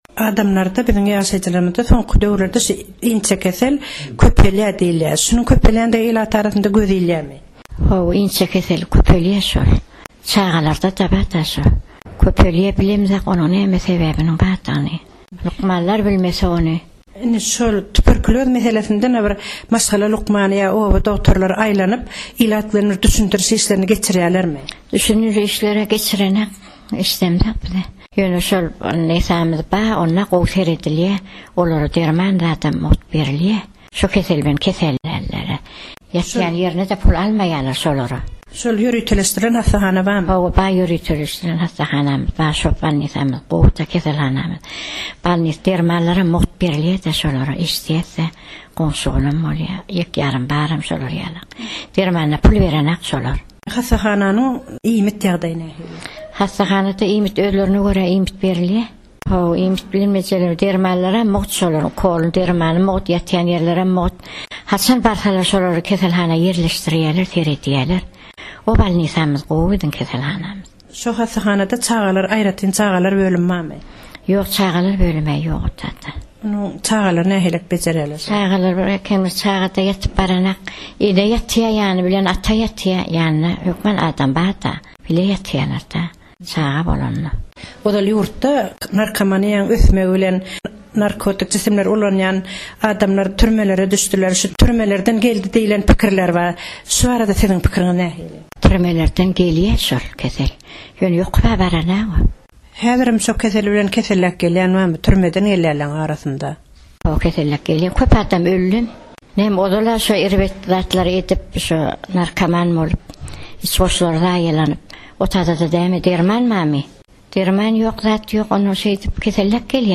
Türkmenistanyň Balkan welaýatynyň raýaty Azatlyk Radiosy bilen söhbetdeşliginde ýurtda inçekeseliň öňüni almak meselesinde ilatyň arasynda zerur düşündiriş işleriň geçirilmeýändigi ýaly kemçilikleriň bardygyny aýtdy.